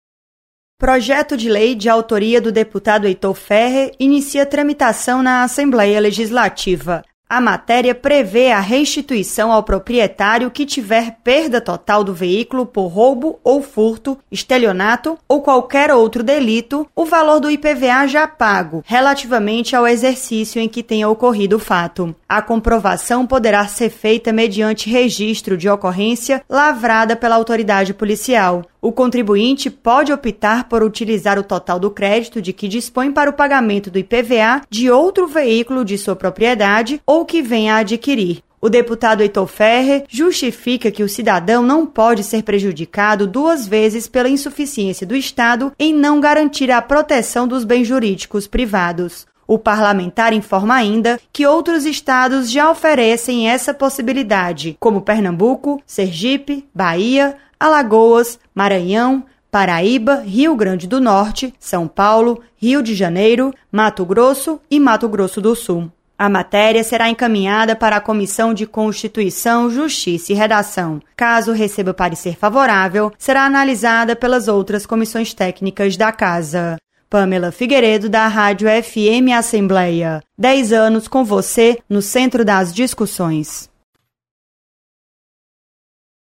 Projeto sugere a restituição do IPVA para veículos roubados e furtados. Repórter